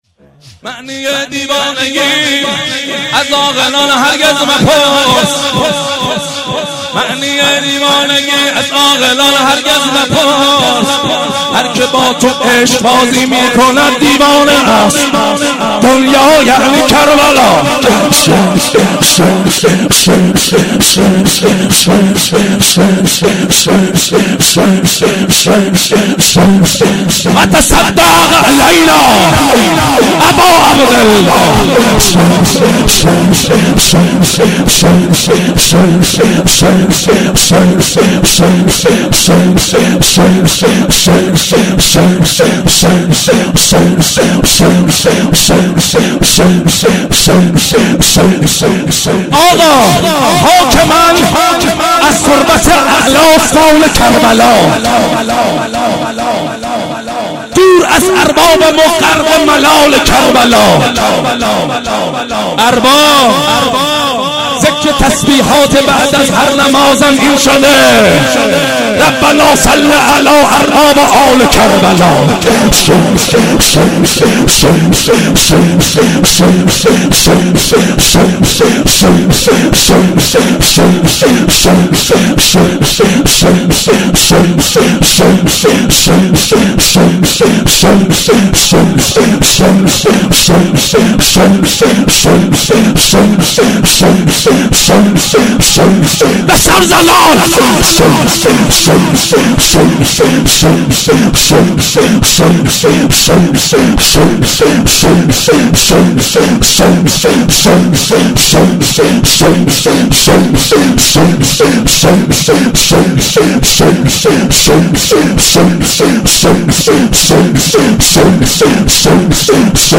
هیئت زواراباالمهدی(ع) بابلسر - رجز و ذکر حسین
شب اول ویژه برنامه فاطمیه دوم ۱۴۳۹